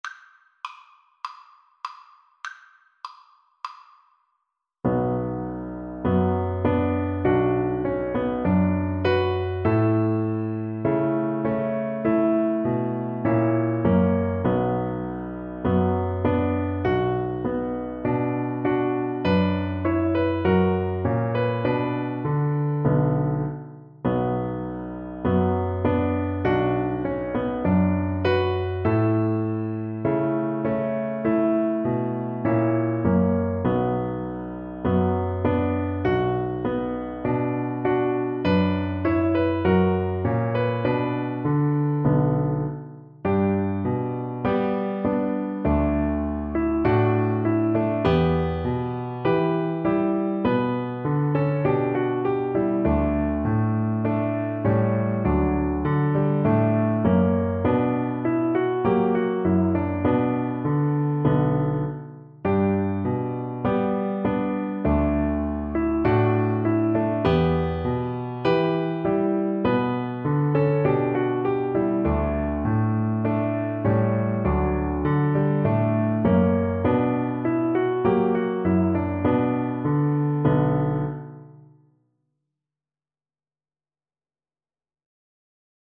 2/2 (View more 2/2 Music)
Steady two in a bar = c.50
Traditional (View more Traditional Violin Music)